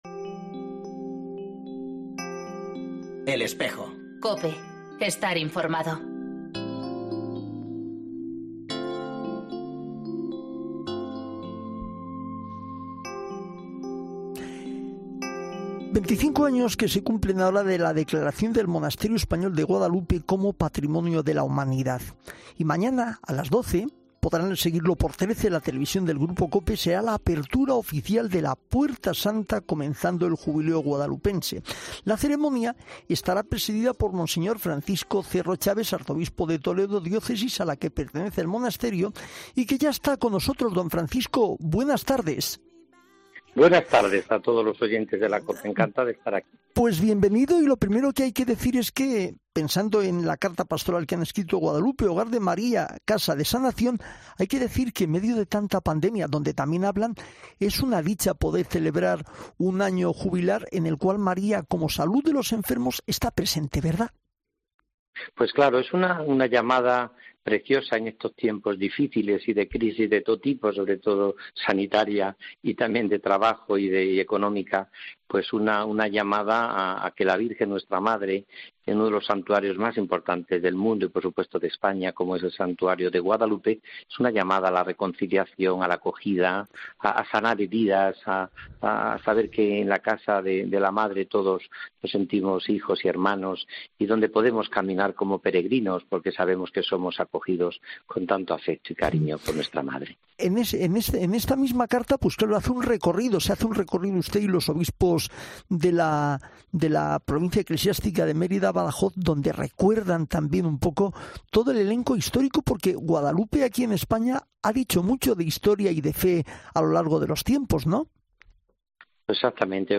Monseñor Francisco Cerro ha estado en 'El Espejo' para mostrar sus sensaciones a unas horas de presidir la ceremonia que dará comienzo al Año...